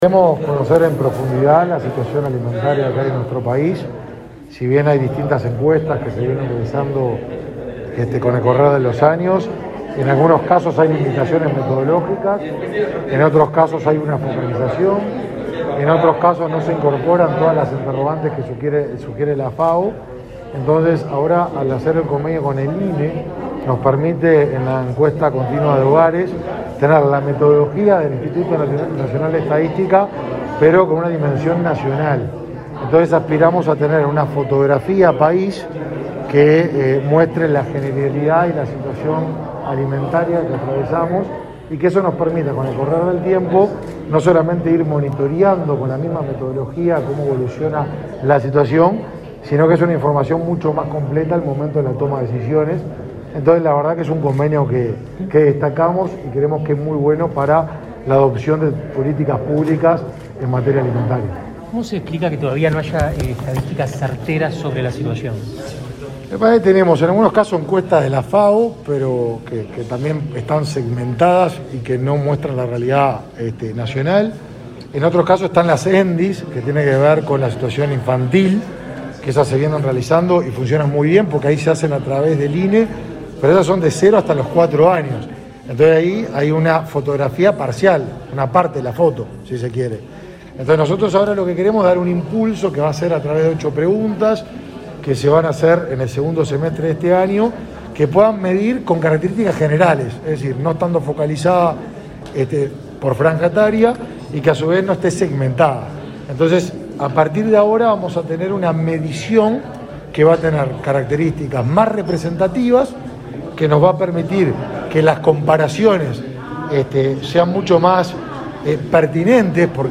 Declaraciones a la prensa del ministro de Desarrollo Social, Martín Lema
Declaraciones a la prensa del ministro de Desarrollo Social, Martín Lema 09/06/2022 Compartir Facebook X Copiar enlace WhatsApp LinkedIn El ministro de Desarrollo Social, Martín Lema, dialogó con la prensa luego de firmar un convenio de cooperación con autoridades del Ministerio de Salud Pública y el Instituto Nacional de Estadística.